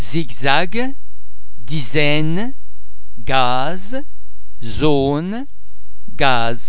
The French [z] sound is normally pronounced [z] as in the English words zero, zebra etc.
·the [z] is pronounced [z] anywhere in the word: